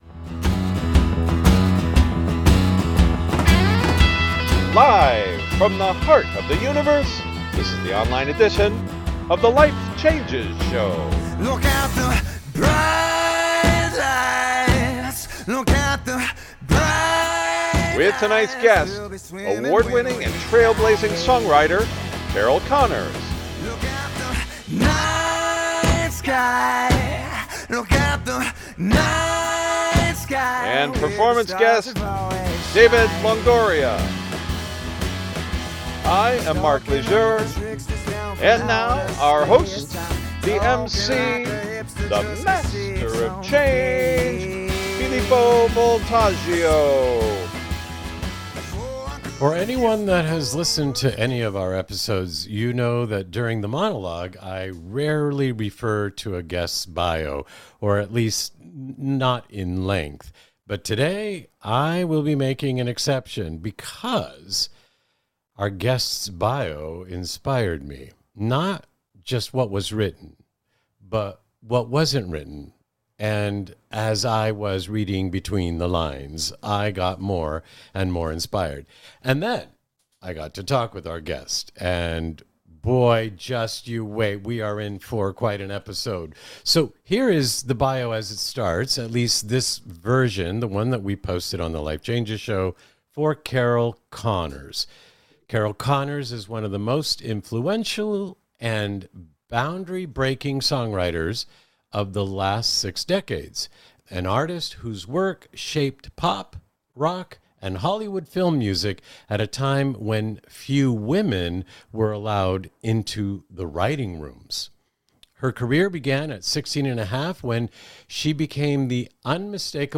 Featuring Interview Guest, Grammy®, Oscar®, Emmy & Golden Globe–Nominated Songwriter, Trailblazing Artist, Pop and Film Music Icon, Carol Connors